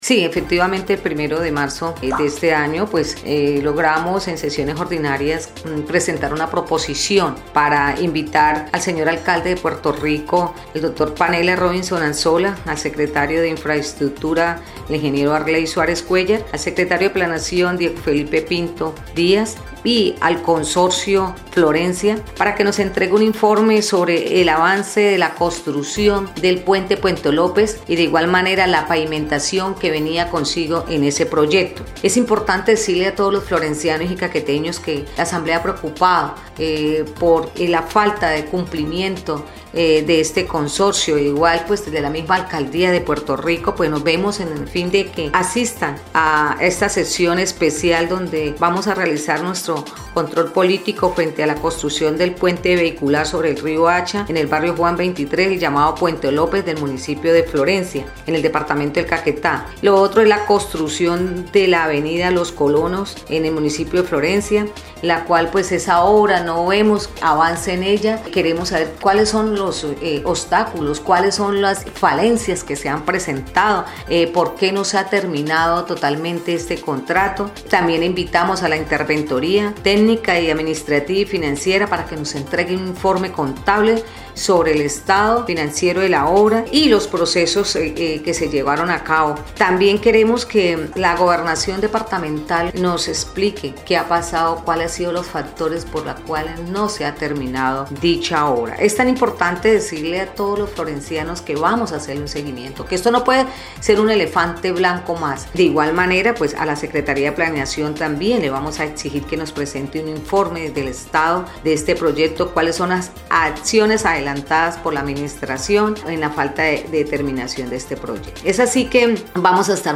Elvia Medina Claros, diputada liberal, autora del llamado, explicó que ese día se escuchará al alcalde de Puerto Rico, Panelas Robinson Anzola, los contratistas y los secretarios de planeación e infraestructura departamental, quienes deberán resolver dudas, especialmente en temas de ejecución y avances.
04_DIPUTADA_ELVIA_MEDINA_PUENTE_LOPEZ.mp3